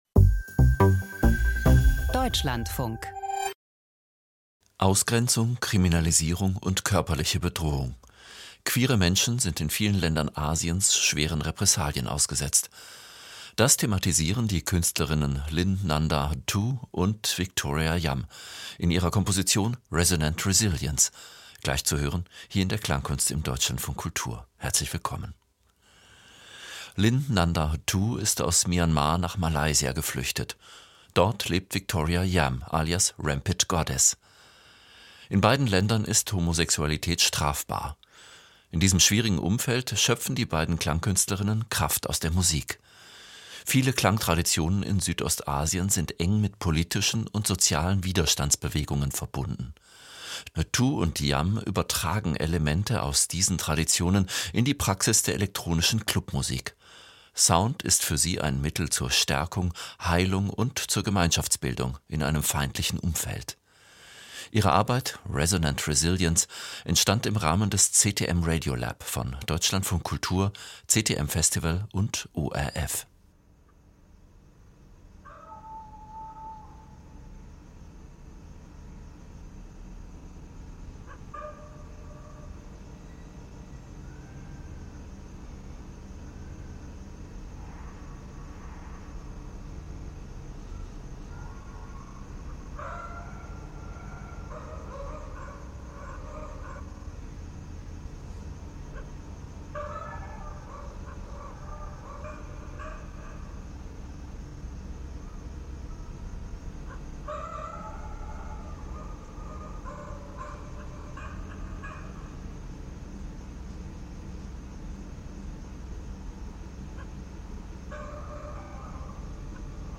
Die vielen Stimmen meines Bruders - Ein Hörspiel in 48 Takes